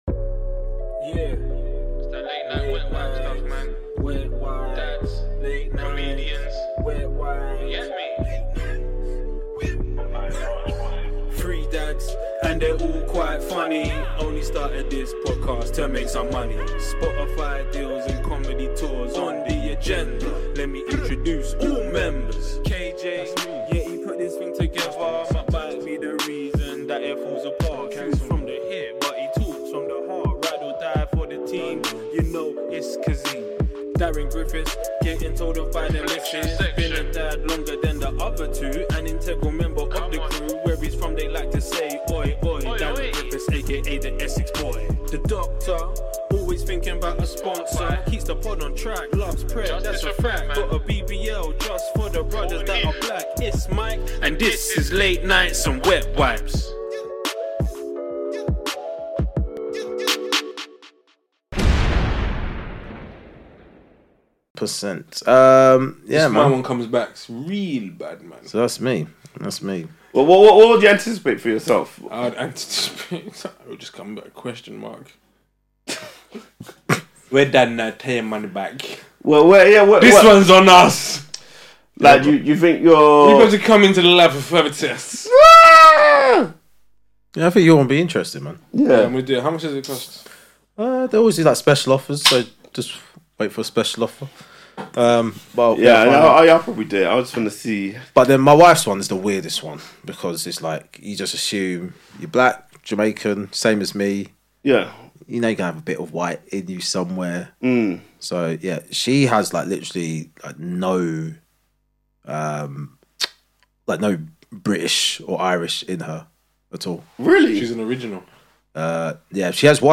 This episode continues, just the three guys solving racism and more genealogy chat.